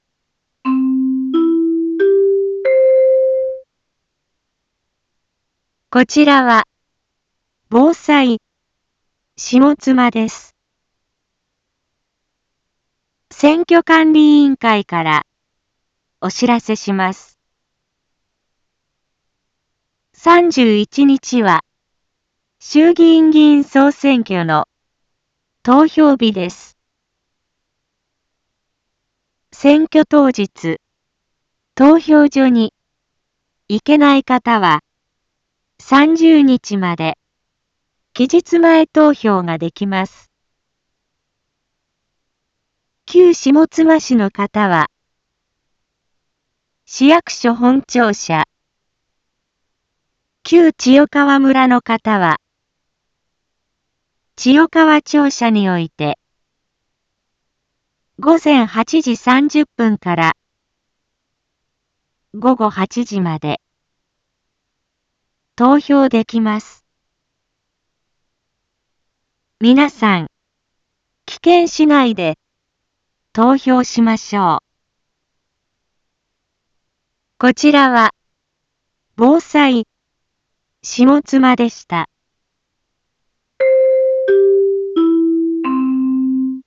一般放送情報
Back Home 一般放送情報 音声放送 再生 一般放送情報 登録日時：2021-10-20 13:01:30 タイトル：衆議院議院総選挙の啓発（期日前投票期間） インフォメーション：こちらは、防災下妻です。